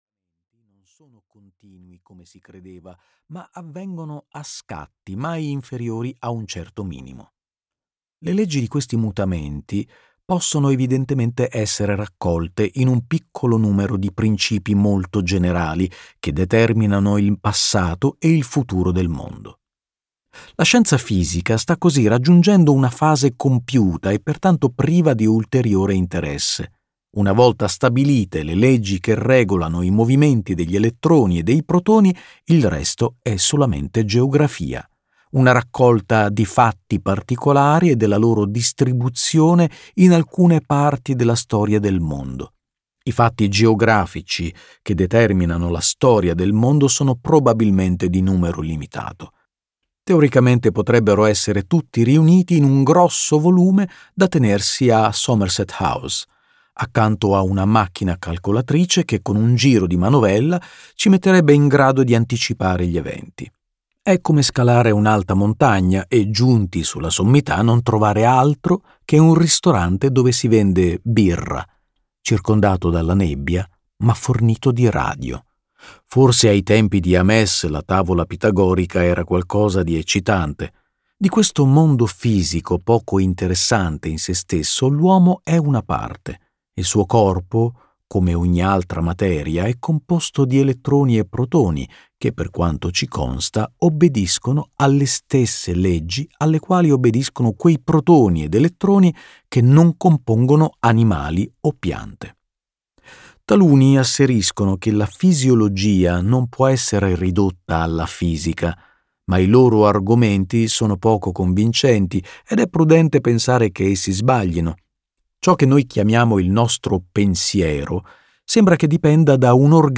"Perché non sono cristiano" di Bertrand Russell - Audiolibro digitale - AUDIOLIBRI LIQUIDI - Il Libraio